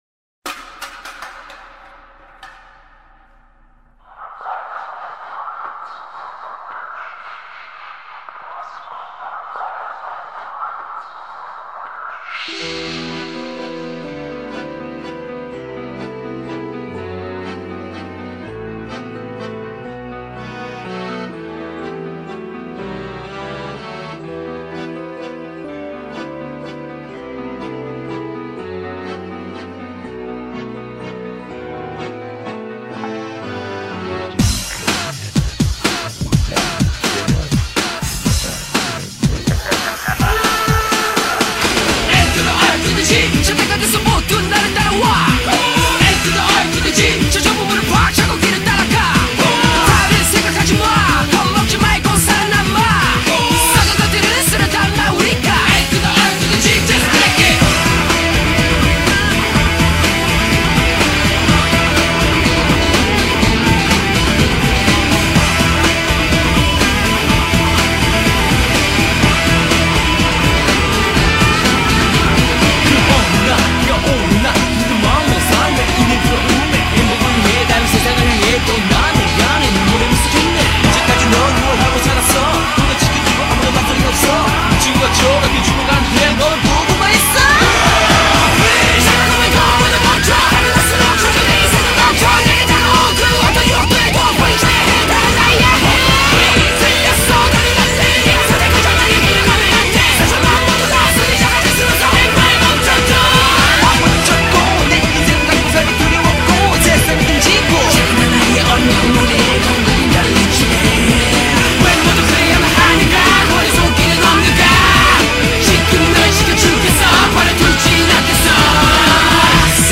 a kpop group on paper.